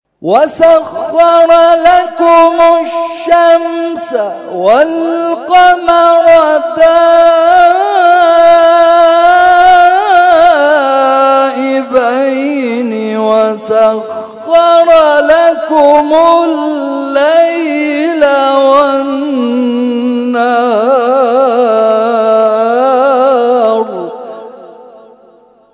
محفل انس با قرآن کریم